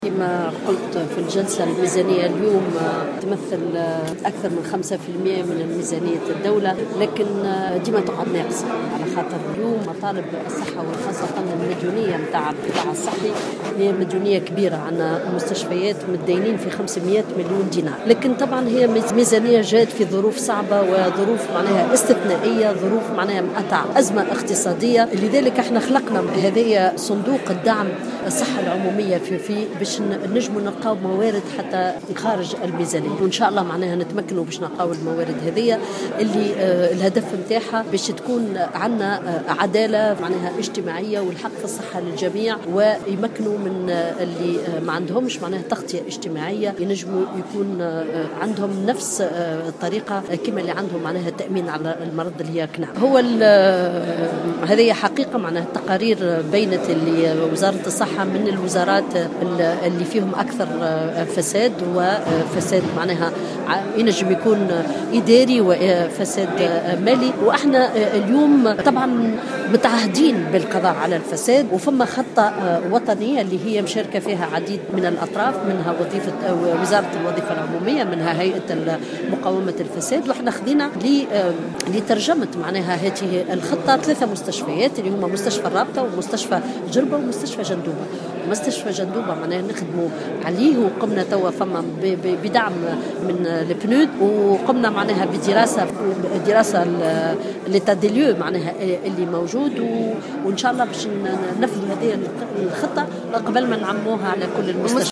واعتبرت وزيرة الصحة سميرة مرعي في تصريح